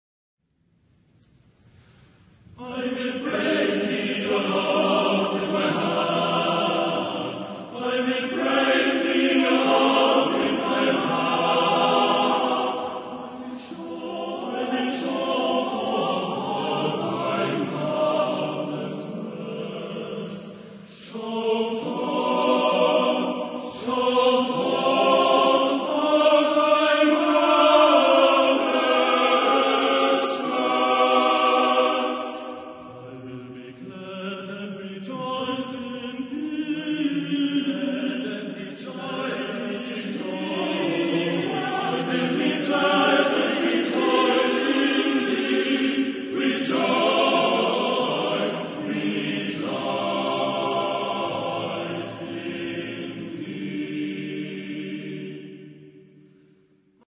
Genre-Stil-Form: Motette ; geistlich
Chorgattung: TTTBB  (5 Männerchor Stimmen )
Tonart(en): frei